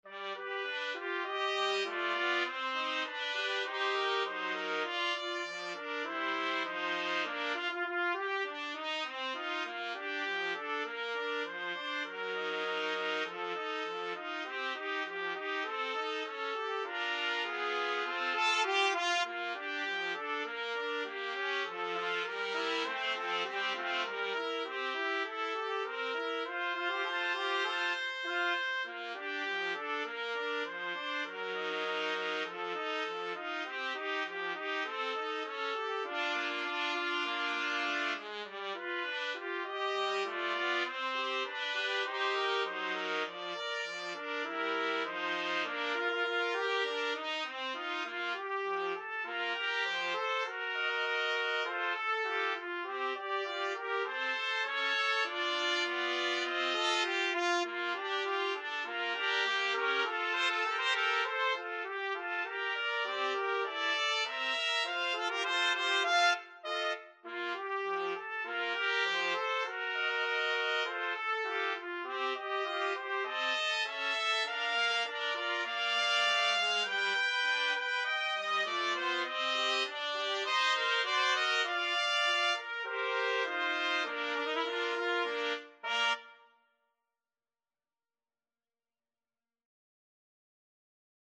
Trumpet 1Trumpet 2Trumpet 3
2/4 (View more 2/4 Music)
Tempo di Marcia
Pop (View more Pop Trumpet Trio Music)